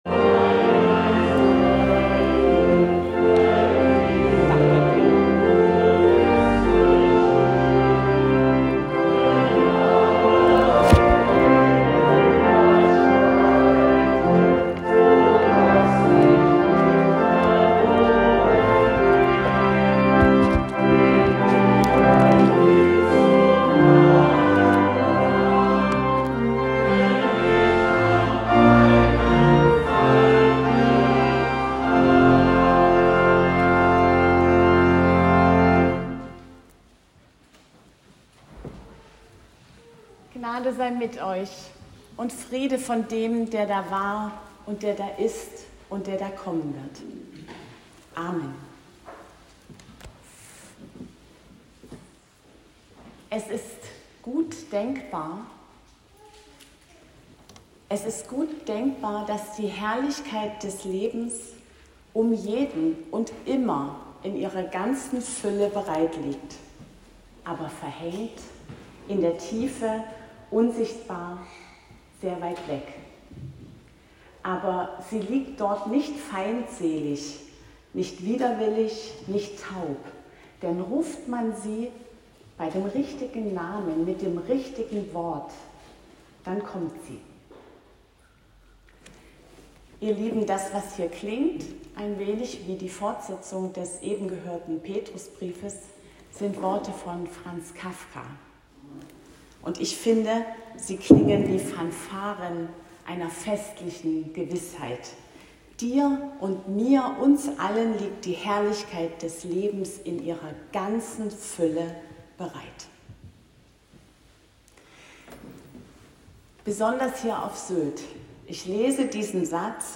Predigt am 6. Sonntag nach Trinitatis, 27.07.2025